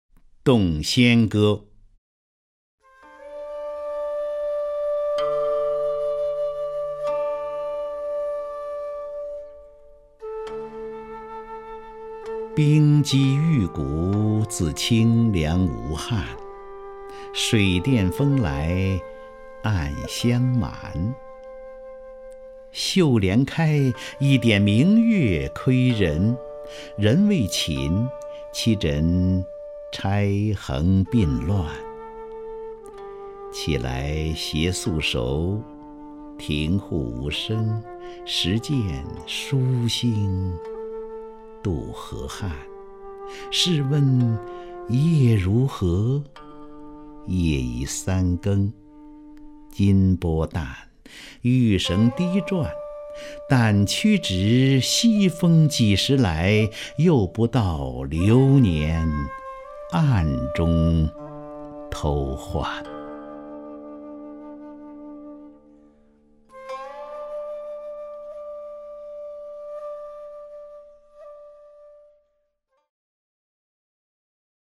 张家声朗诵：《洞仙歌·冰肌玉骨》(（北宋）苏轼)
名家朗诵欣赏 张家声 目录